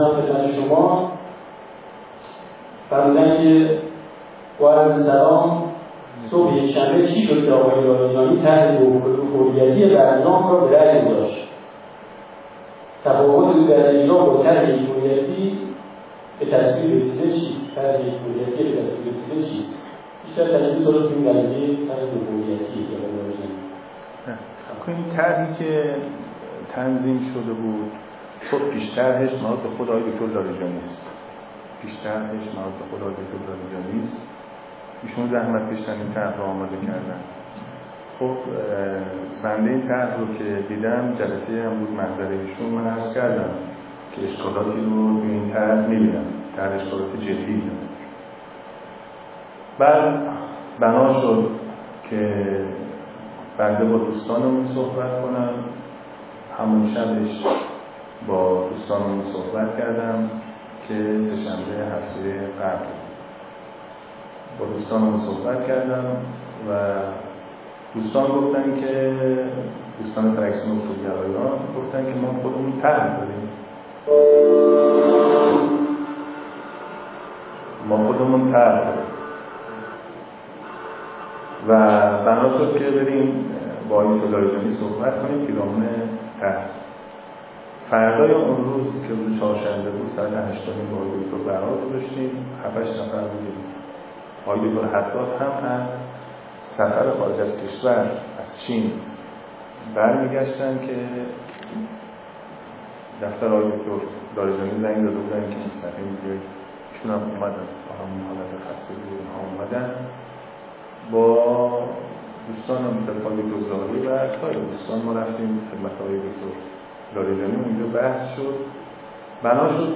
پرسش و پاسخ 940716.mp3